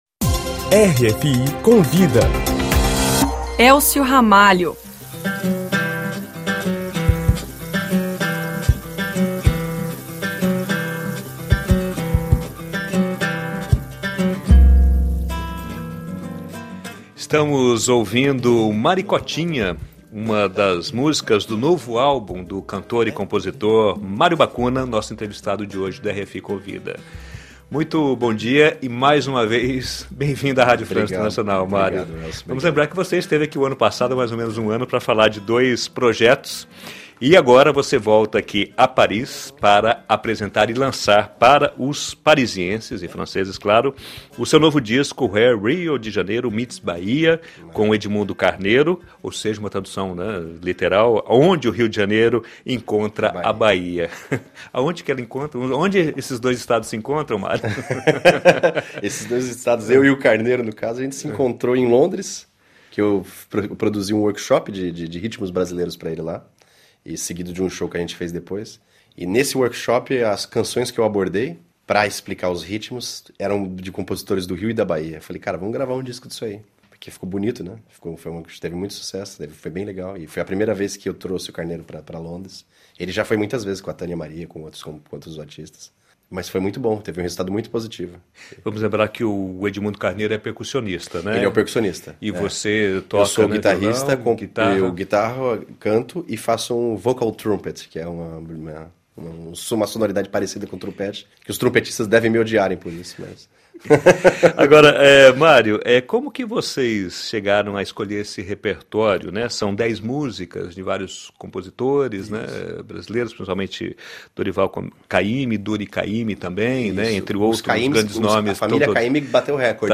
nos estúdios da RFI Brasil